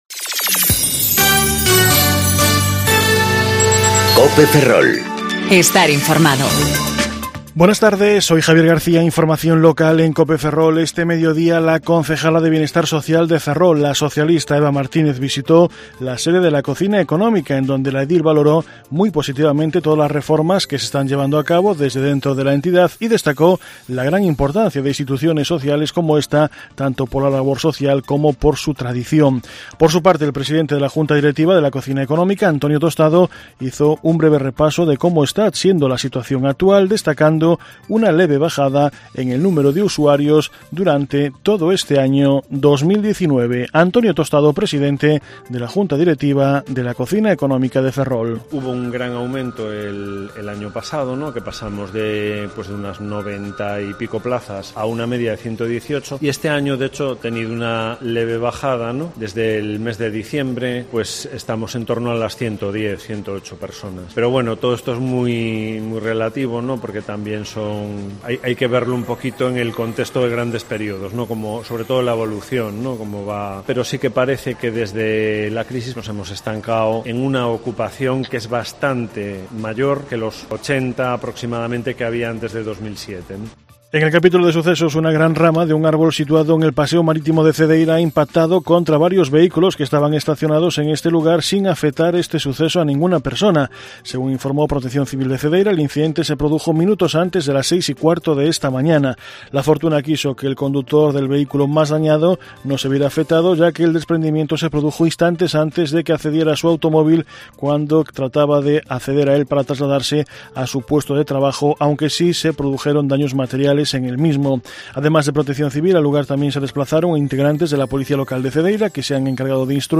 Informativo Mediodía Cope Ferrol 7/8/2019 (De 14.20 a 14.30 horas)